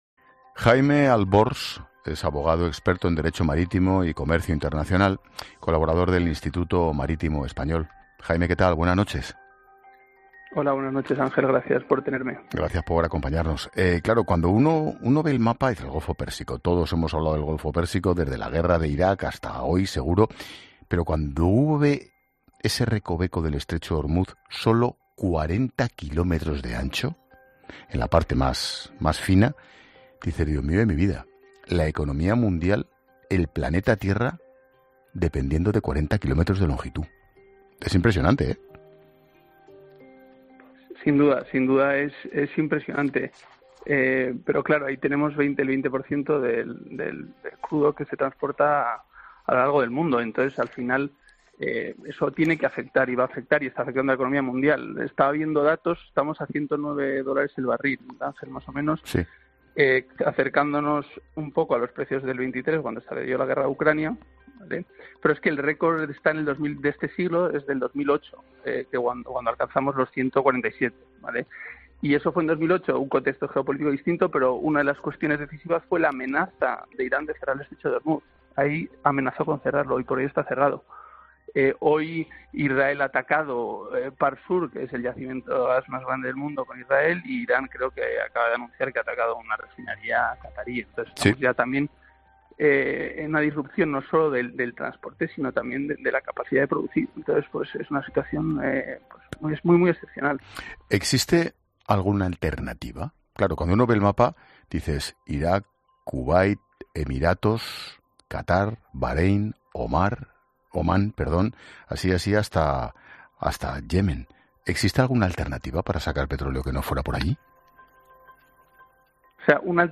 Expósito entrevista